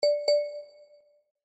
notification-sound.1457bdf9.mp3